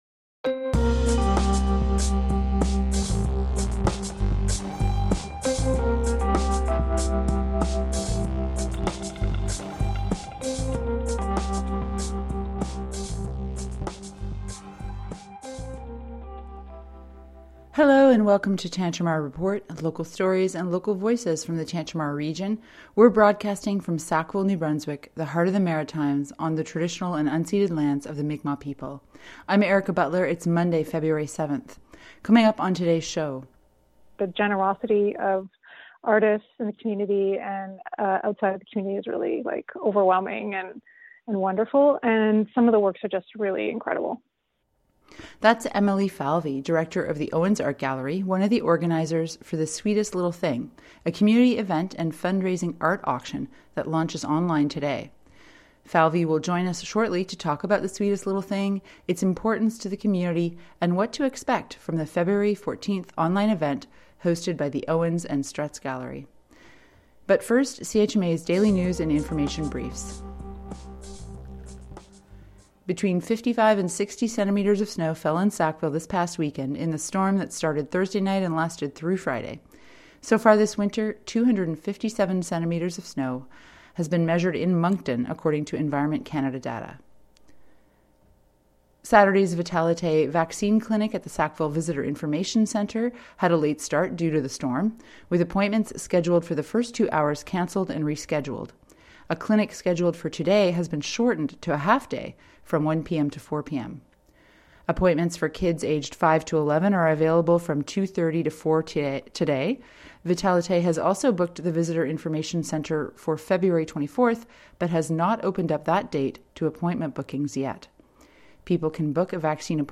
CHMA - CHMA - Sackville • NB